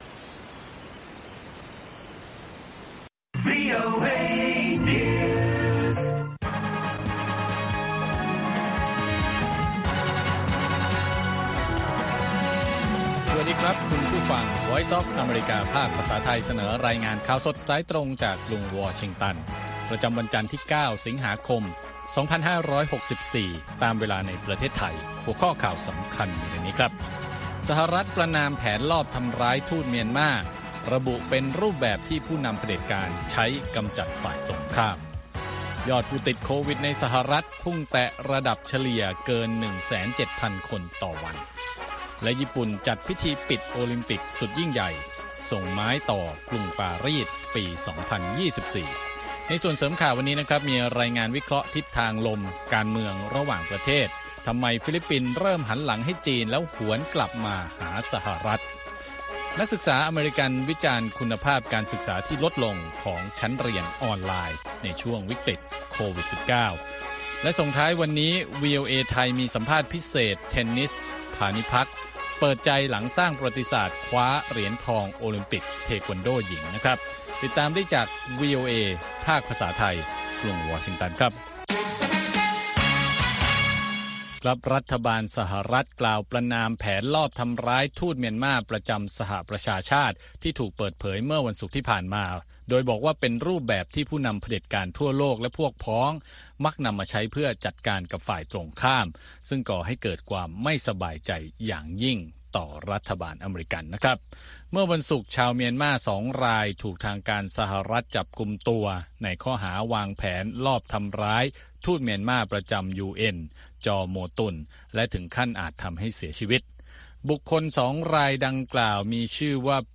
ข่าวสดสายตรงจากวีโอเอ วันจันทร์ ที่ 9 สิงหาคม 2564